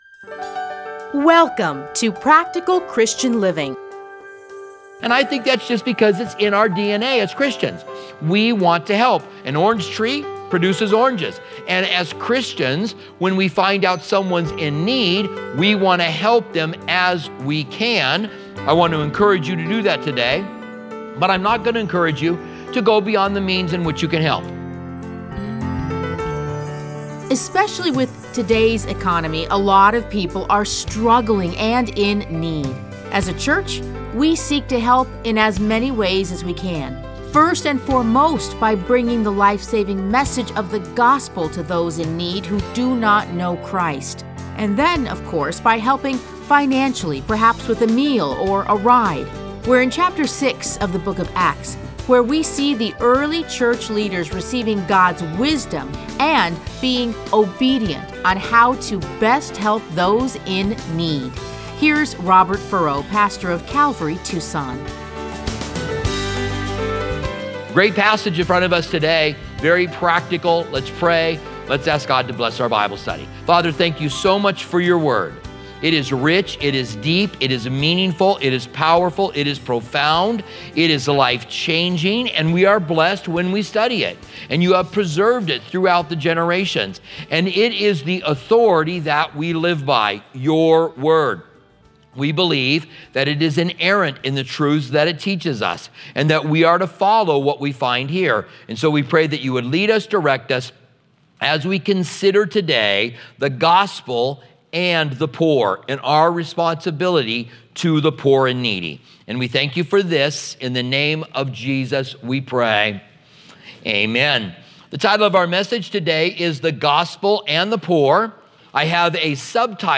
Listen to a teaching from Acts 6:1-7.